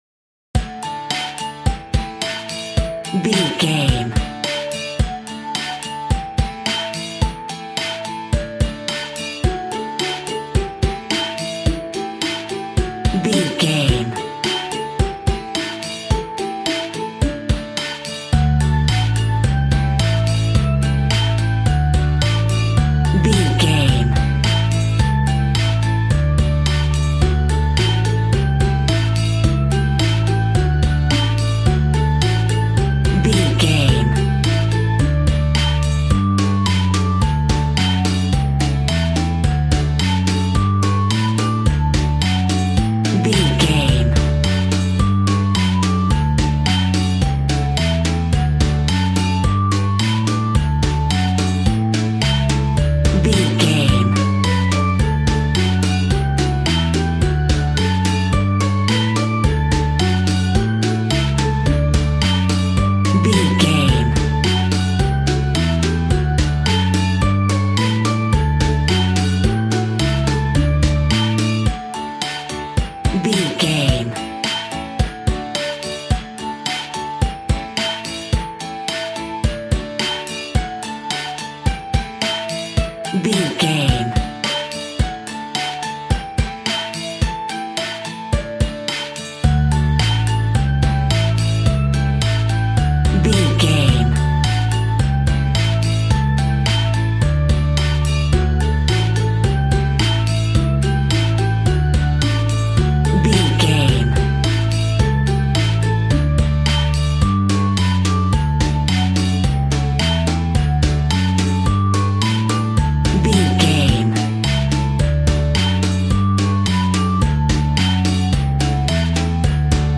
Ionian/Major
D
Fast
instrumentals
fun
childlike
cute
kids piano